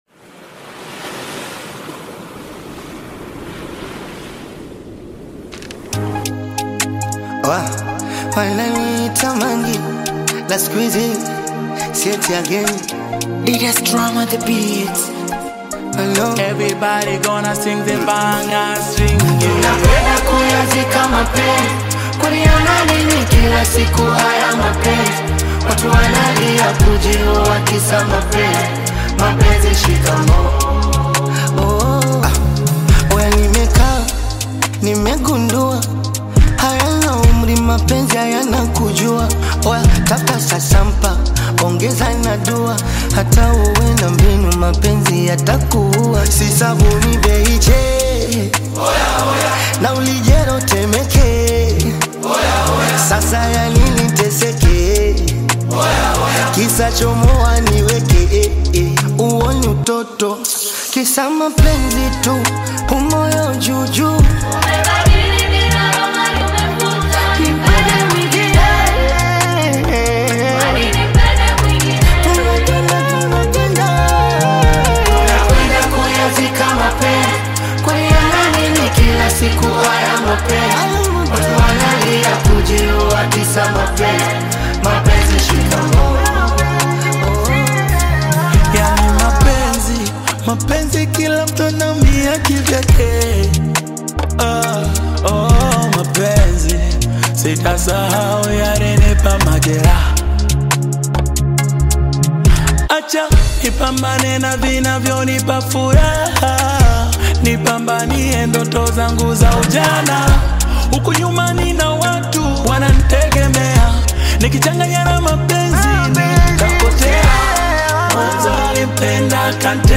is an Afro-beat remix